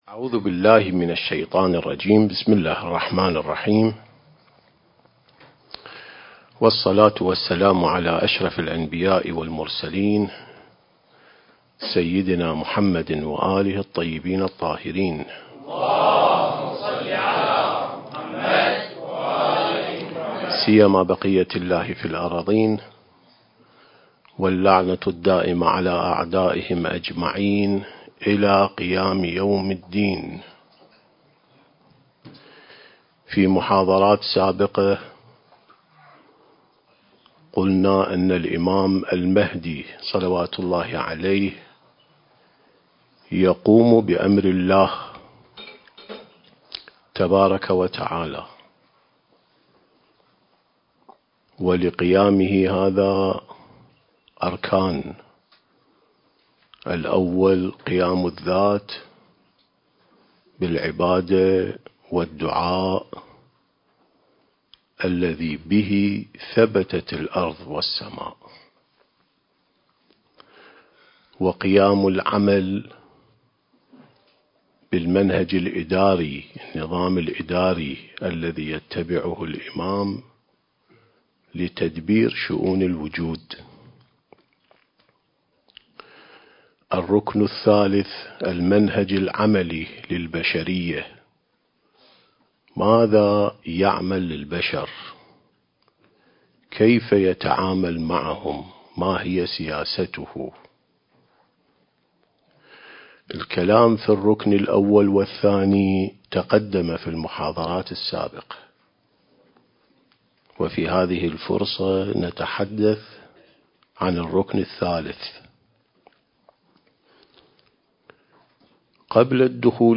سلسة محاضرات الإعداد للمهدي (عجّل الله فرجه) (1) التاريخ: 1444 للهجرة